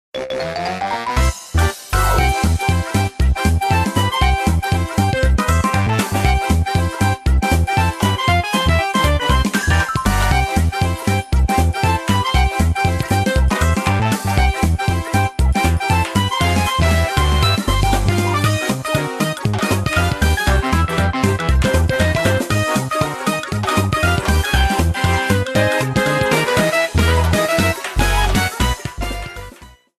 Fair use music sample